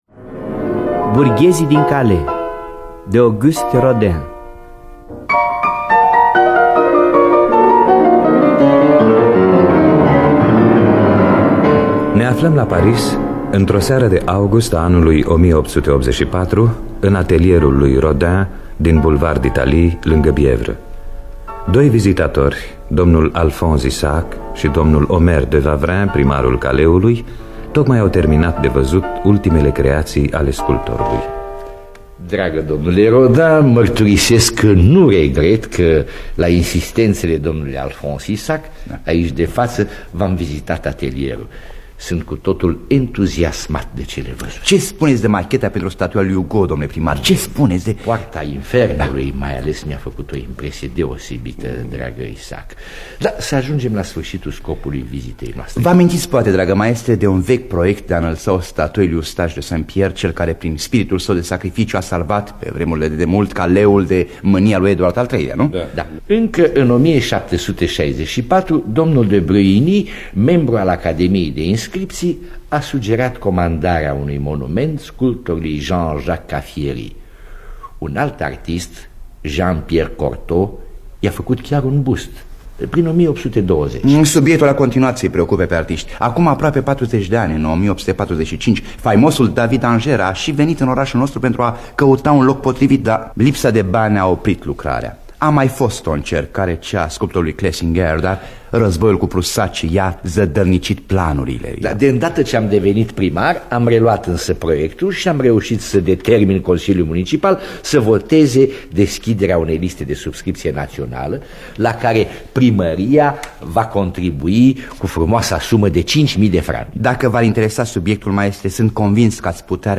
Biografii, Memorii: Auguste Rodin – Burghezii Din Calais (1976) – Teatru Radiofonic Online